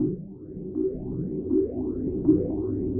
Index of /musicradar/rhythmic-inspiration-samples/80bpm